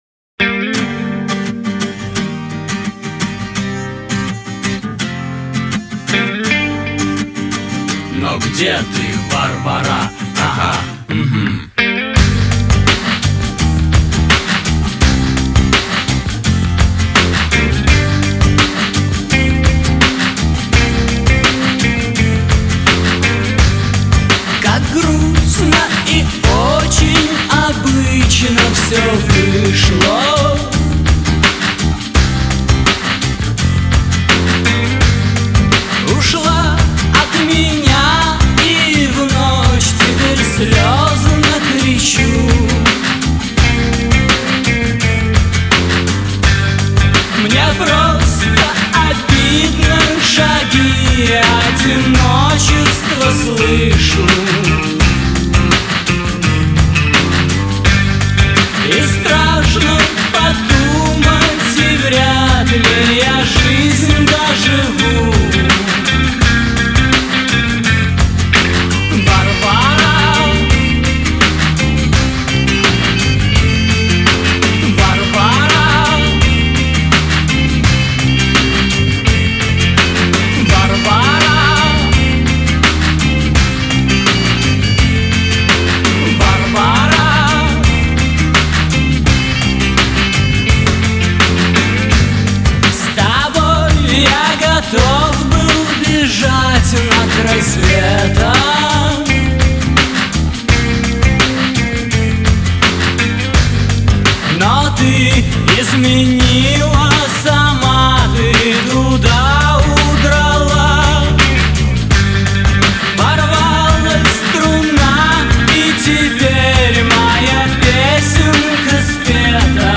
Рок музыка
русский рок